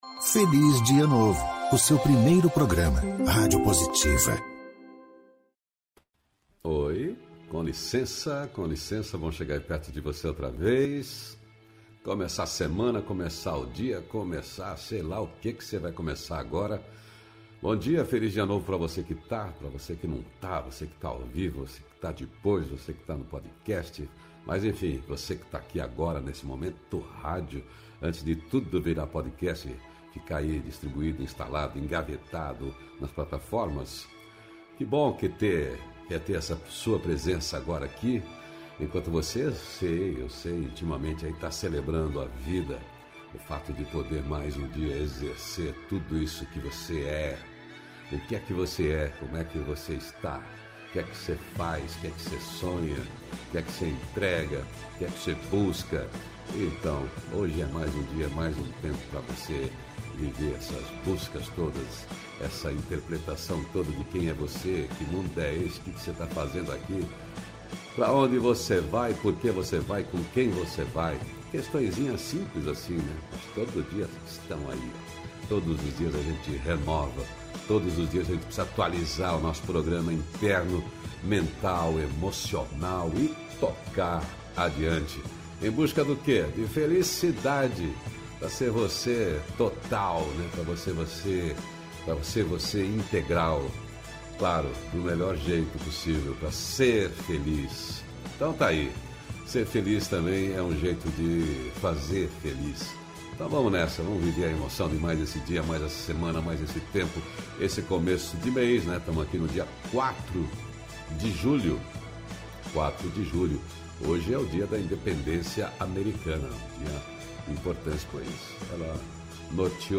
Conversa com quem tem o que dizer de bom.
O programa é um contraponto leve ao noticiário hard predominante na mídia tradicional de rádio e tv. O Feliz Dia Novo, é uma revista descontraída e inspiradora na linguagem de rádio (agora com distribuição via agregadores de podcast), com envolvimento e interatividade da audiência via redes sociais.